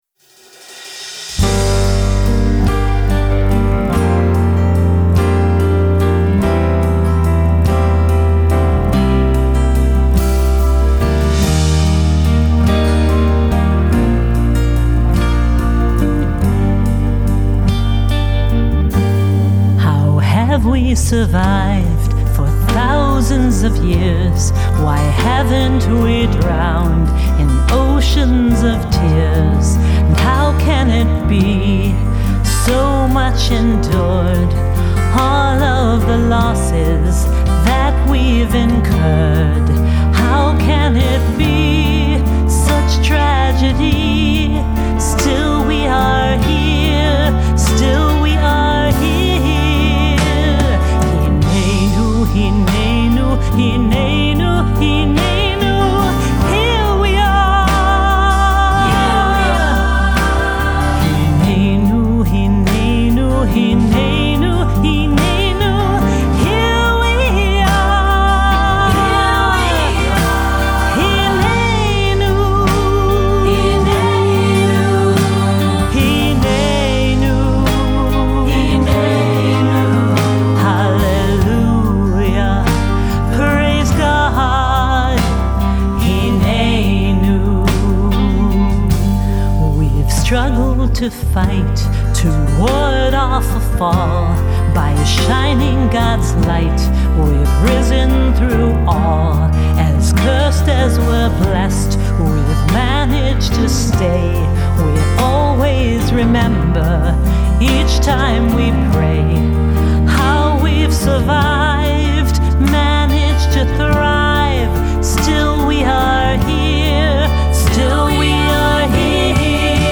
Composer & Cantorial Soloist